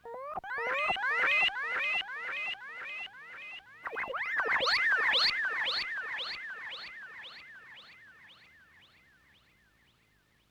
Cat Sequence.wav